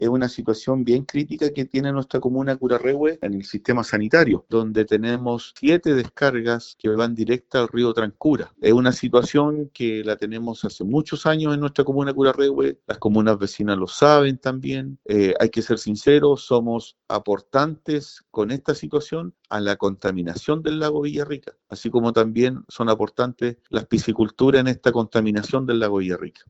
En entrevista con Radio Bío Bío, Parra admitió que existen siete descargas activas desde la red de alcantarillado urbano hacia el río, una situación que -según sus palabras- “todos saben”, incluyendo las autoridades sanitarias y medioambientales
alcalde-de-curarrehue.mp3